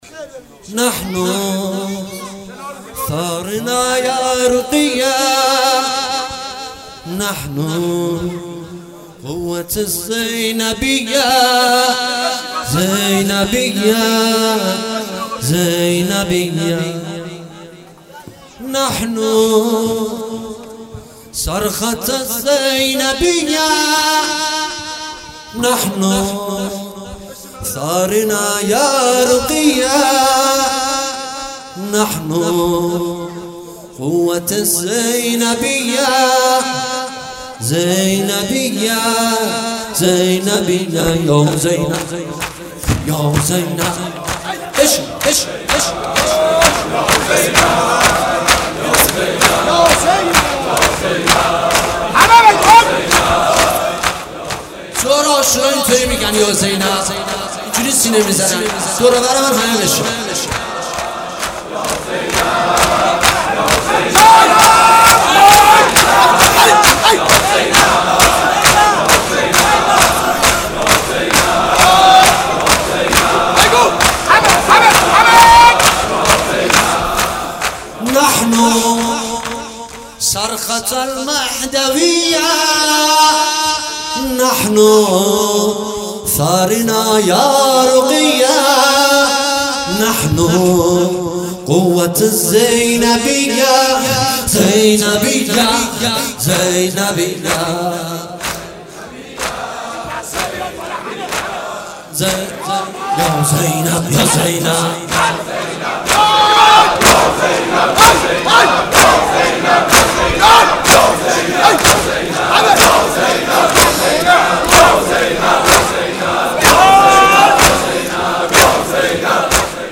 مراسم صبح عاشورا ۱۳۹۶
مداح